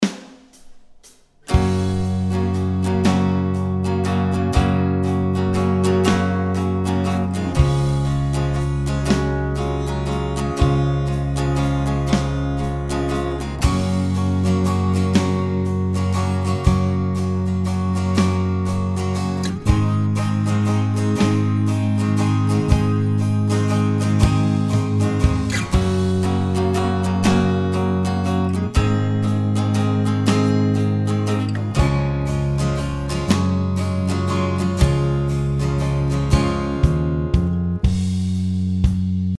Here's a quick and nasty strum comparing it to my old (modern) yamaha. The clip flicks between the two guitars - modern one first.